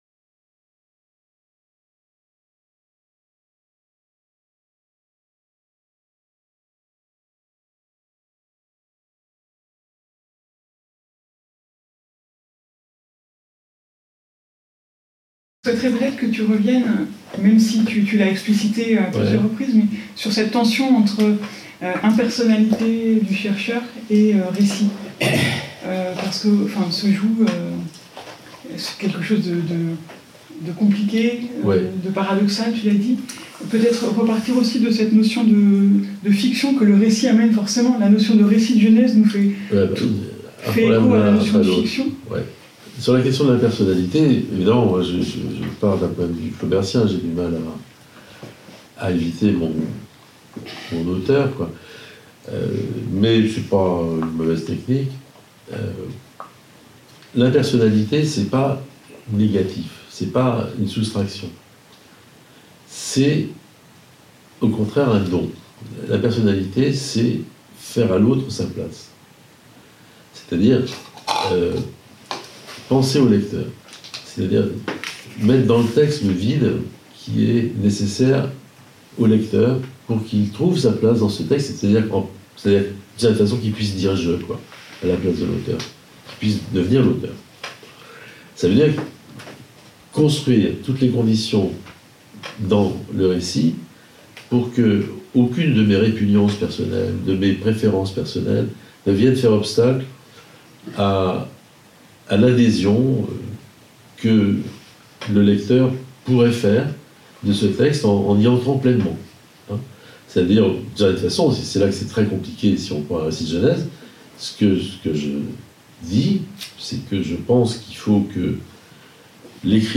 Masterclass sur le Récit de genèse, deuxième partie | Canal U
Pensée sur le format d'une discussion entre plusieurs participants